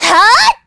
Isaiah-Vox_Attack4_kr.wav